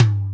add drum sound samples from scratch 2.0
Tom(1)_22k.wav